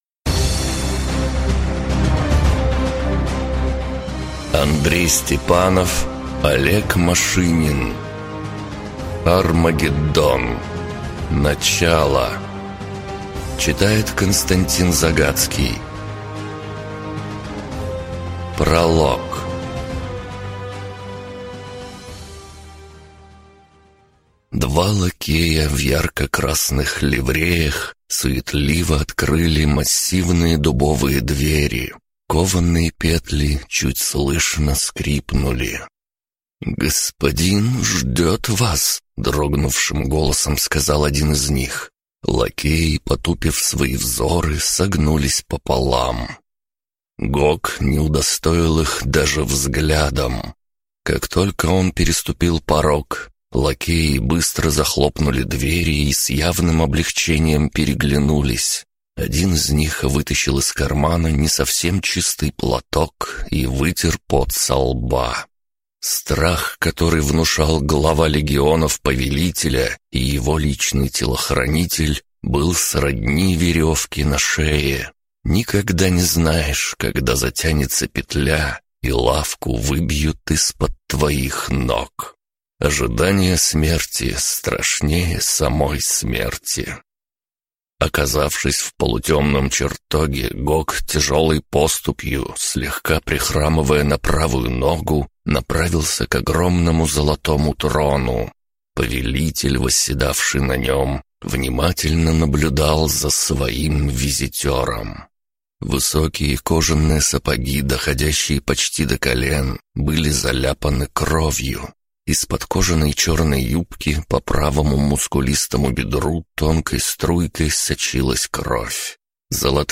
Аудиокнига Армагеддон: Начало | Библиотека аудиокниг